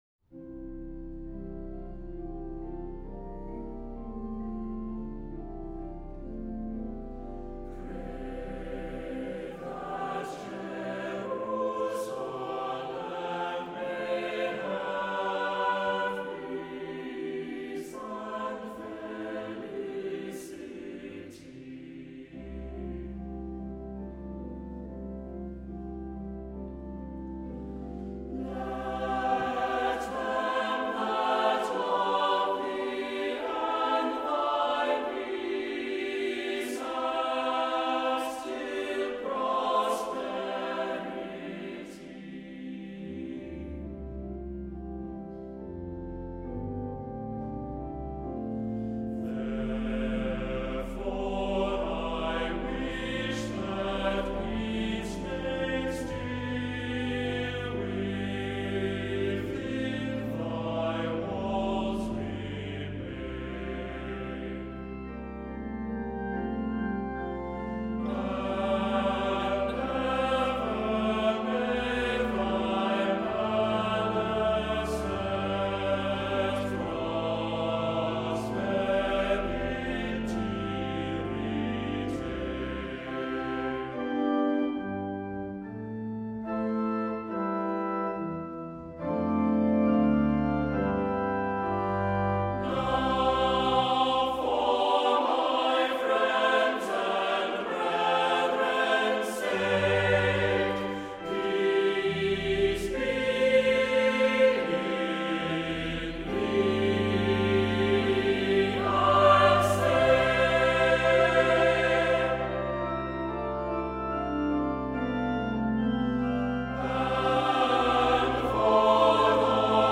Accompaniment:      With Organ
Music Category:      Christian